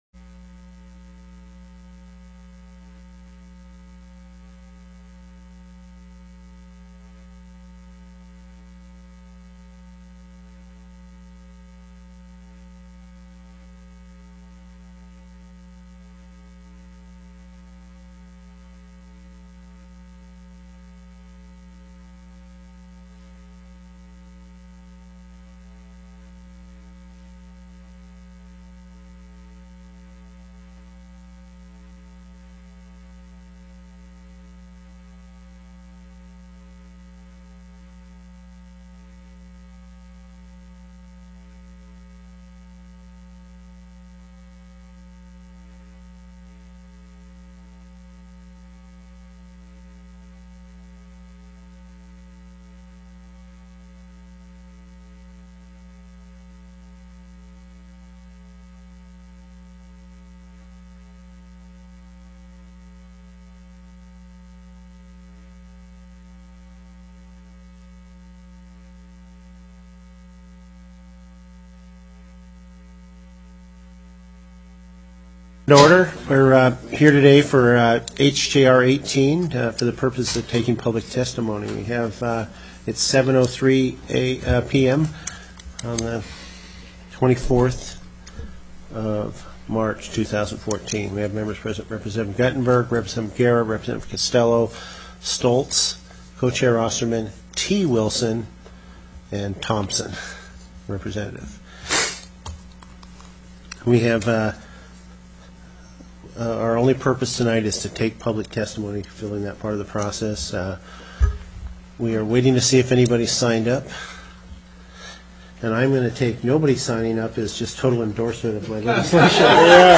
HOUSE JOINT RESOLUTION NO. 18 Proposing amendments to the Constitution of the State of Alaska relating to the office of attorney general. 7:03:55 PM Co-Chair Stoltze stated that the purpose of the meeting was to take public testimony.